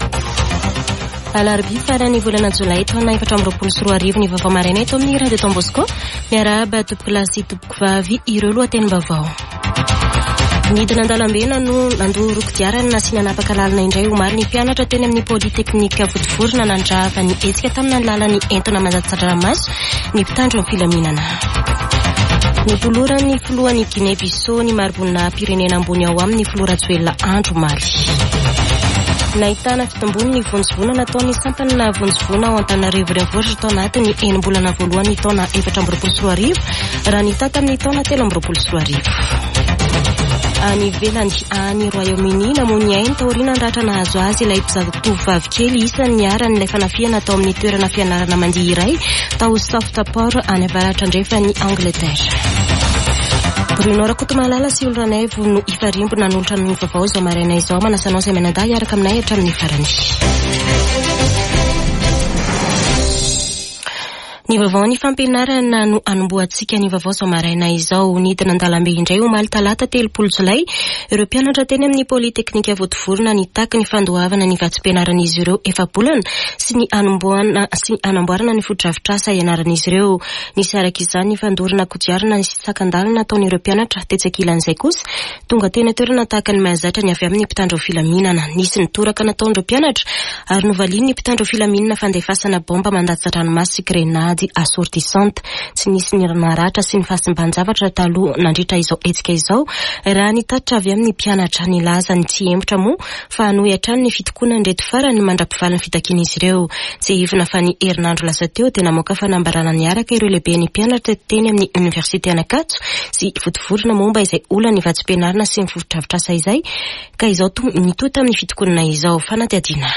[Vaovao maraina] Alarobia 31 jolay 2024